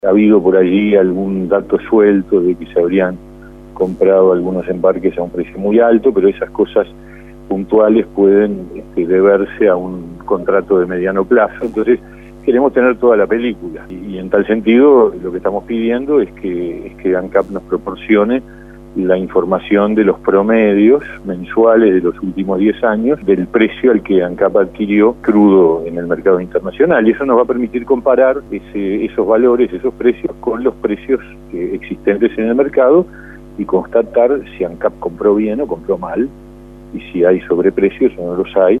El senador del Partido Independiente, Pablo Mieres, en entrevista con El Espectador, disertó sobre su decisión de no votar el proyecto de ley que establece que los particulares que poseen establecimientos rurales de más de 300 hectáreas deban pagar el Impuesto a Primaria.